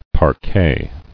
[par·quet]